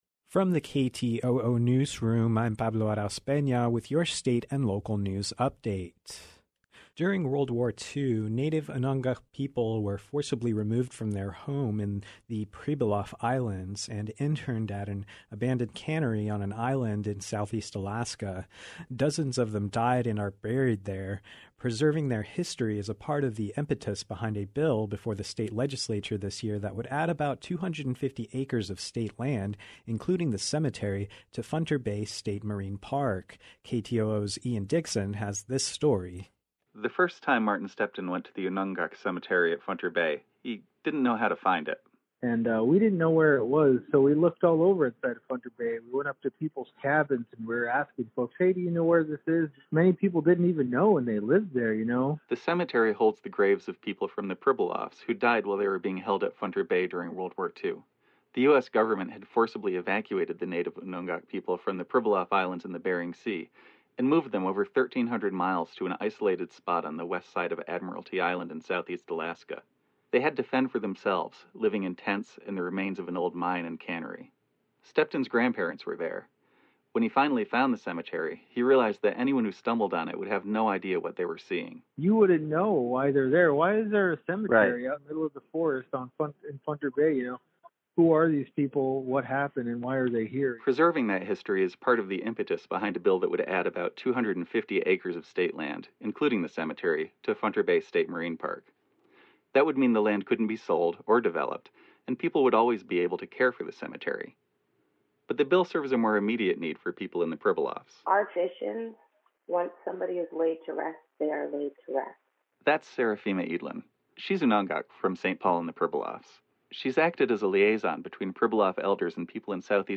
Newscast – Friday, Feb. 5, 2021